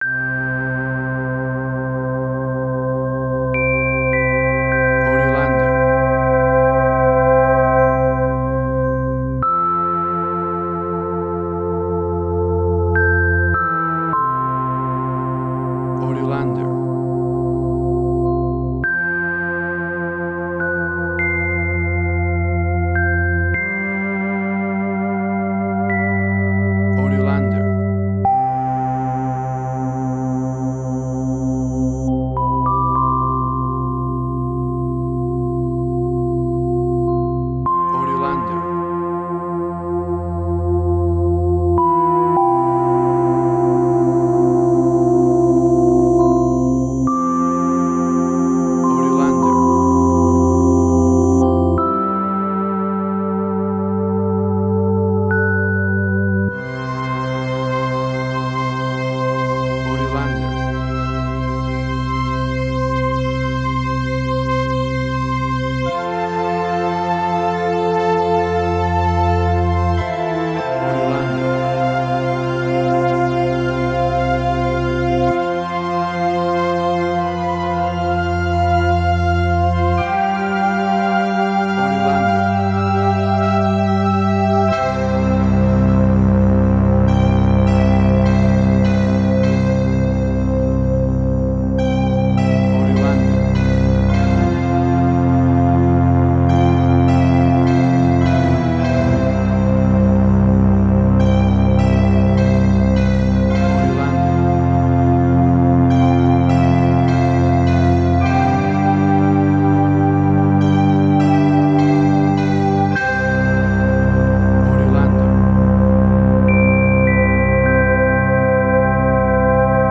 New Age.
emotional music